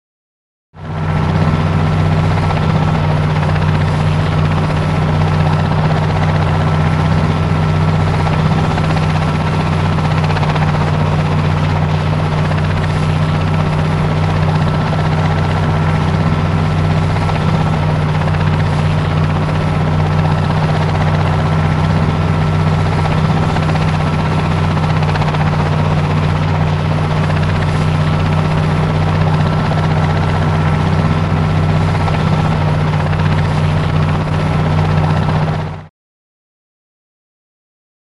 Helicopter; Interior; Scout Helicopter Interior Constant.